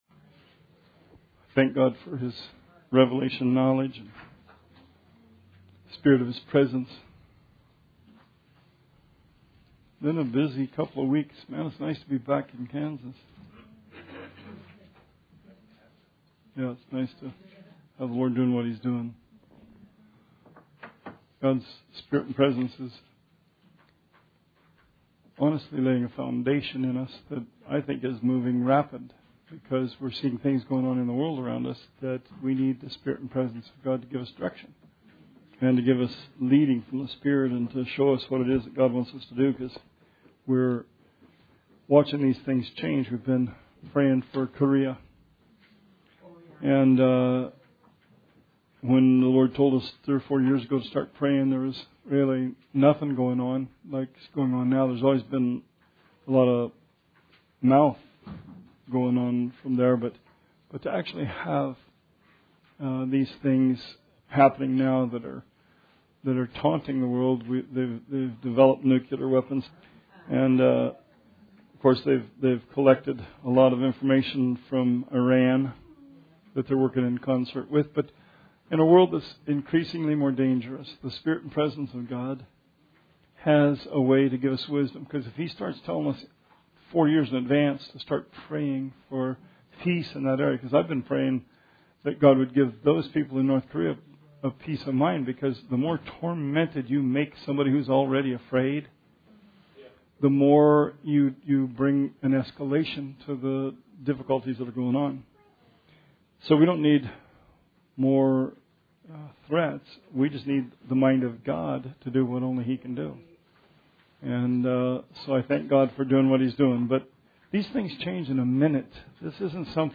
Bible Study 8/30/17